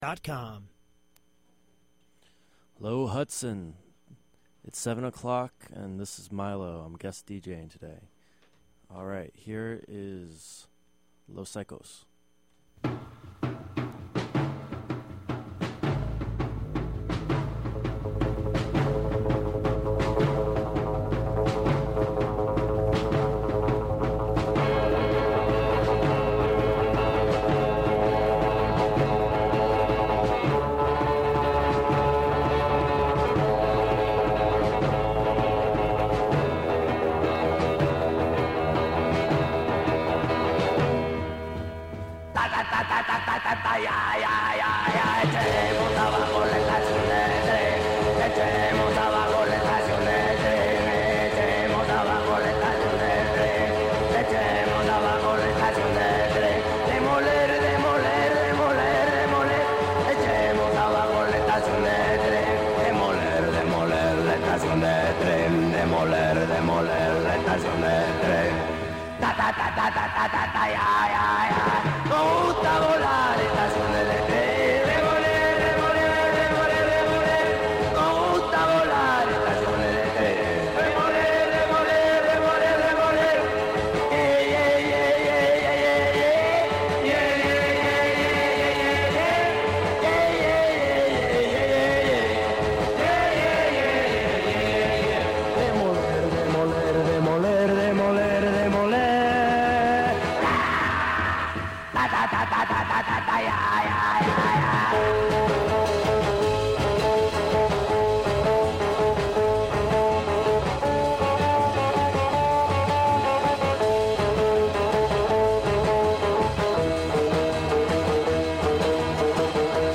Guest DJ
Recorded Dec. 9, 2011 at WGXC.